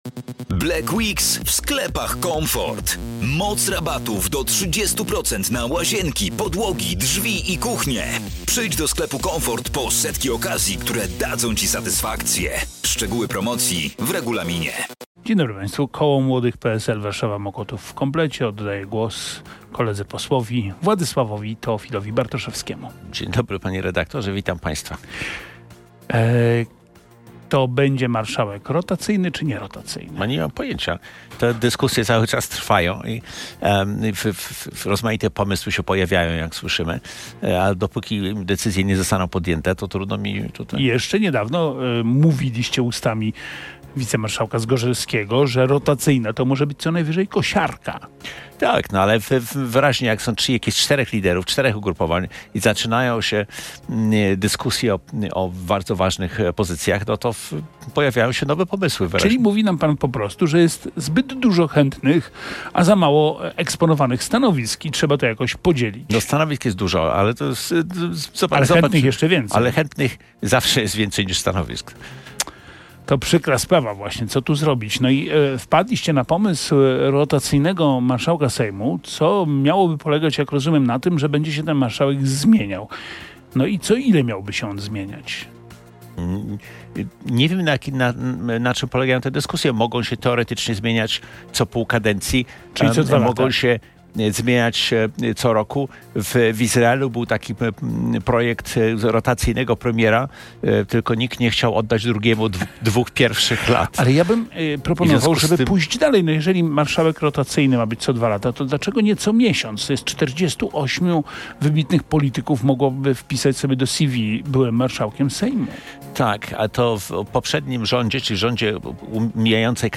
w wywiadzie dla RMF FM